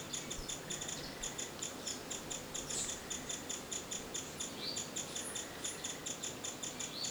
Amazilia versicolor
Foraging call  recorded PROCOSARA, PN San Rafael